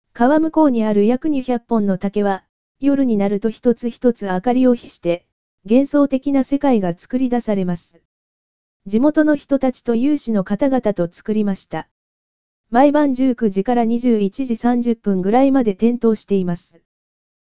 四万灯り – 四万温泉音声ガイド（四万温泉協会）